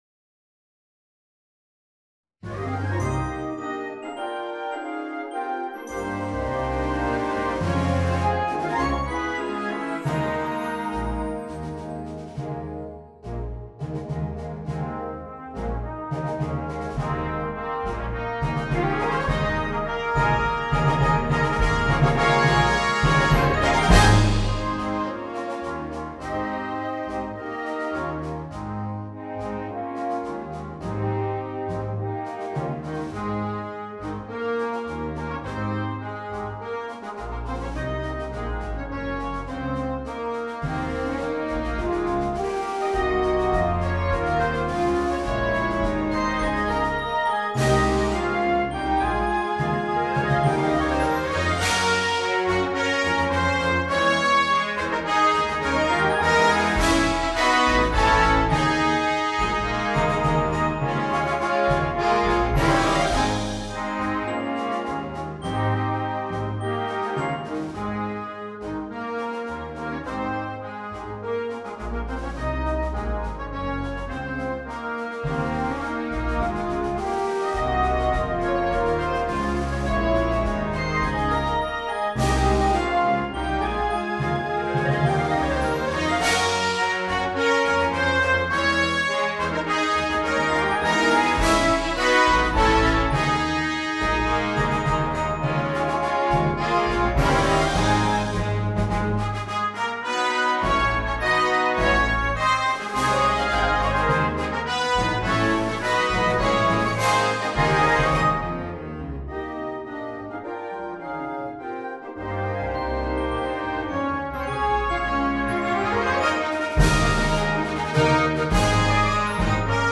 Partitions pour orchestre d'harmonie.
• View File Orchestre d'Harmonie